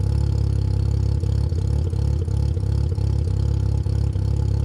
wr_02_idle.wav